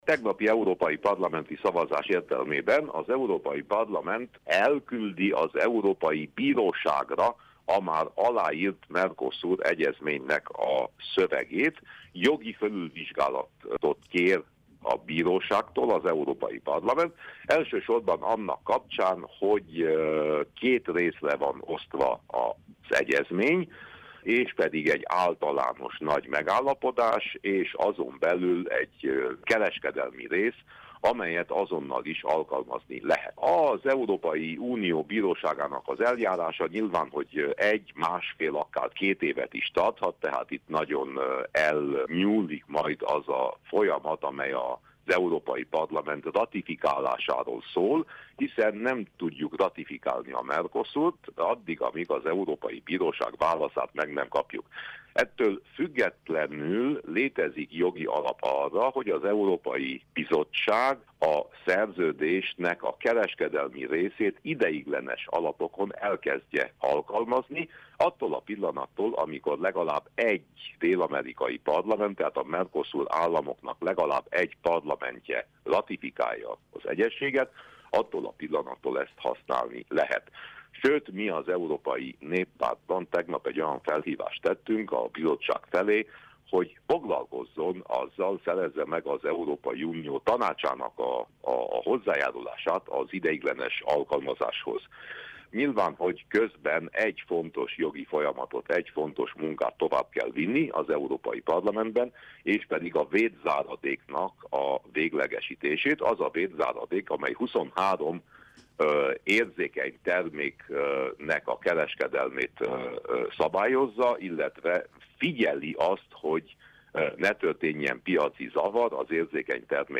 Winkler Gyula EP képviselőt kérdezte